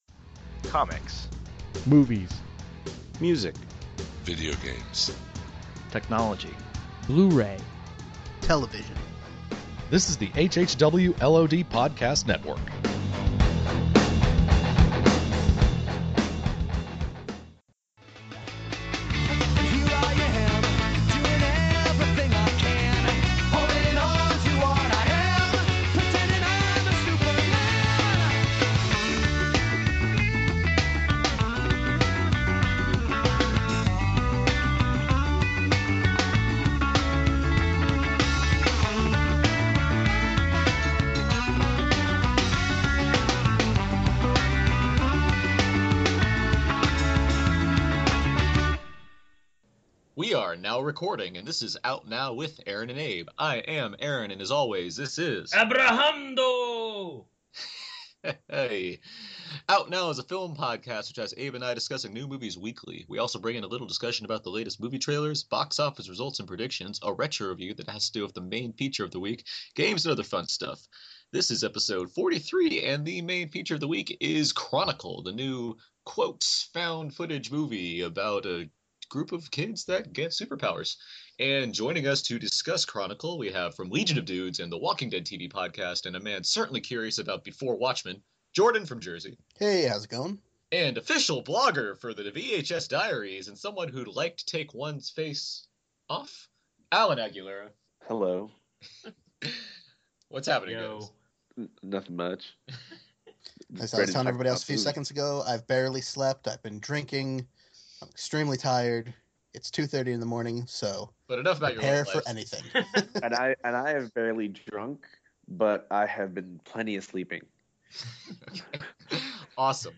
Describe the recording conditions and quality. It’s a show recorded late at night, but everyone is more than game to play along, using telekinesis or otherwise…